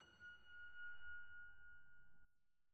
Piano Nudes Mve 2 Artificially Sustained Notes.wav